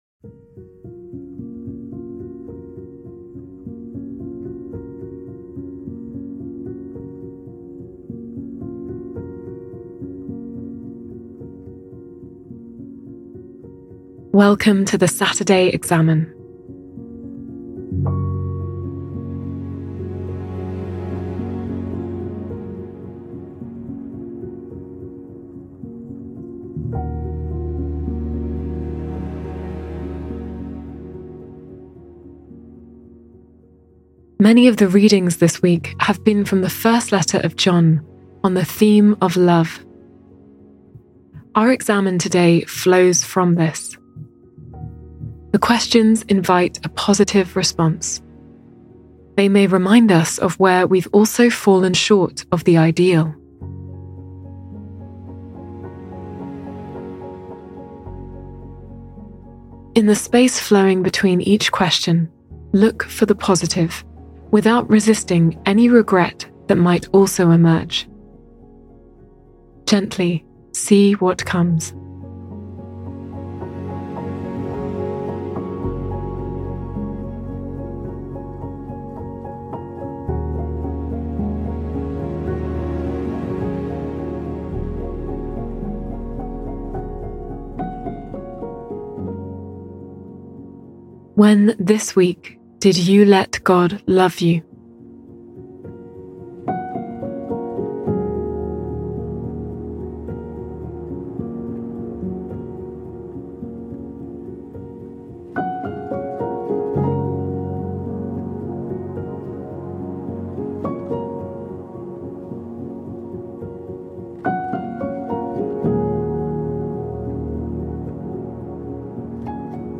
Listen to guided prayer, every day, for free.